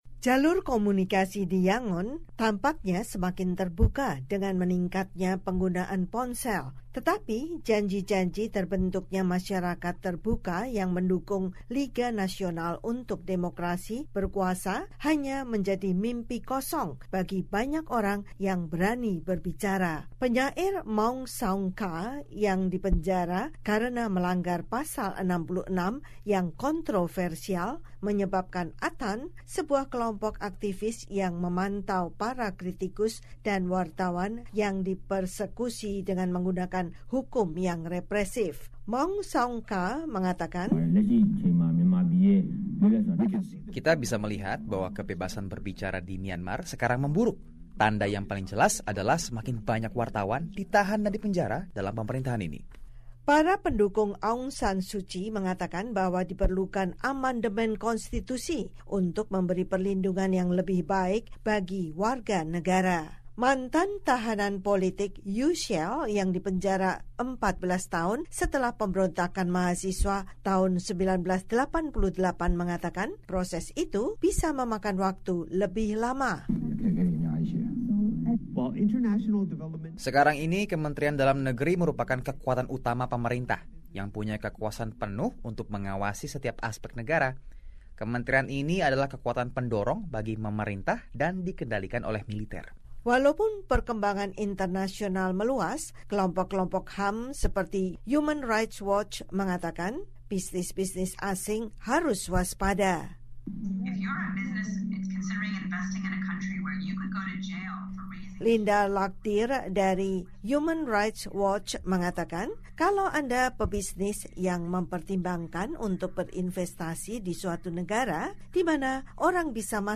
Sejumlah aktivis dan pekerja HAM di Myanmar mengatakan, meningkatnya penumpasan terhadap media, kebebasan berbicara dan berkumpul, menciptakan iklim penyensoran sendiri dan ketakutan di negara itu. Laporan